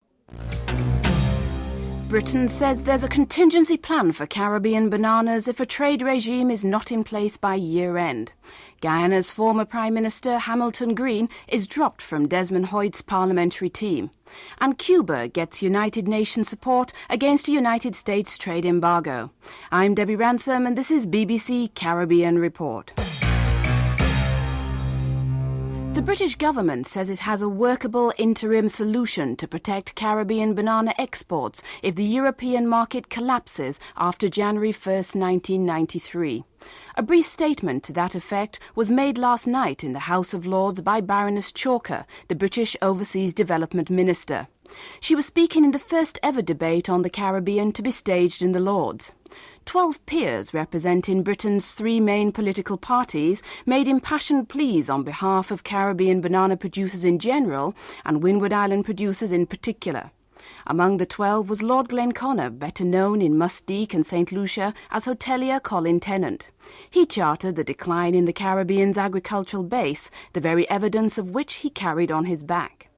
The British Broadcasting Corporation
1. Headlines (00:00-00:24)